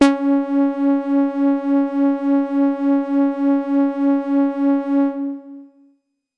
标签： CSharp5 midinote73 DSITetra synthesizer singlenote multisample
声道立体声